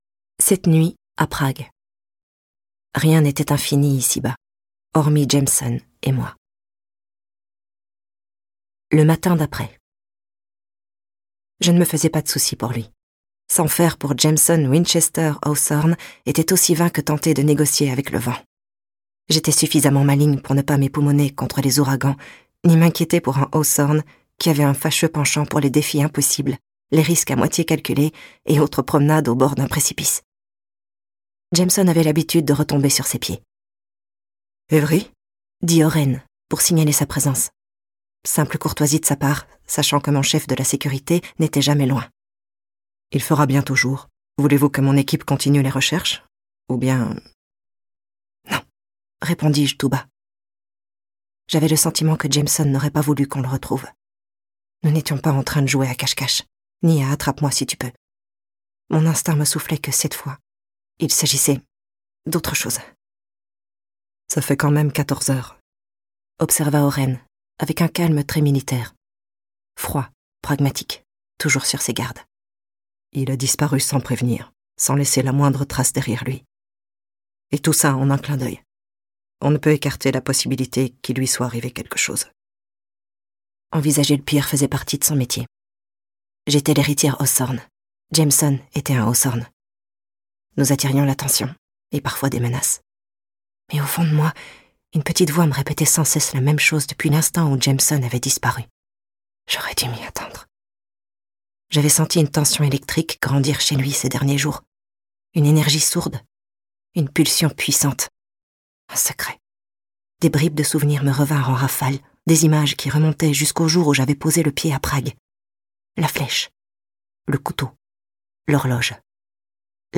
je découvre un extrait - Inheritance Games - Tome 5 : Games Untold - Les ultimes secrets - la saga young adult événement.